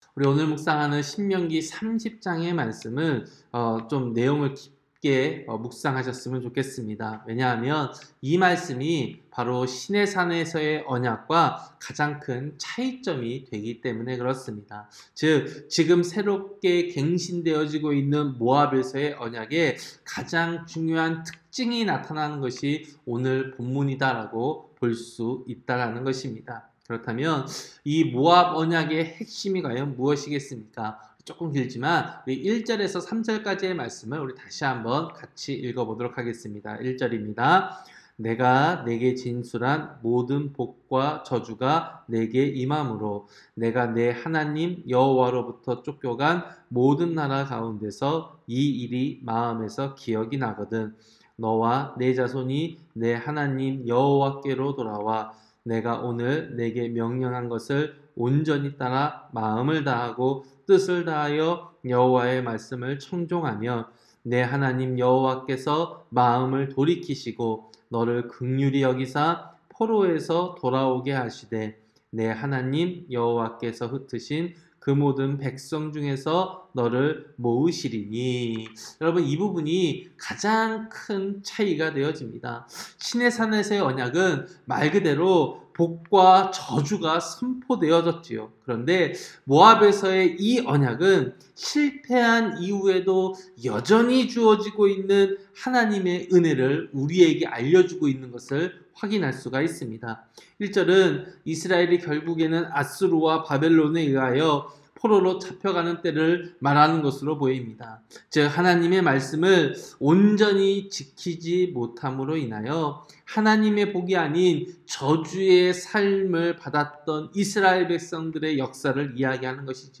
새벽설교-신명기 30장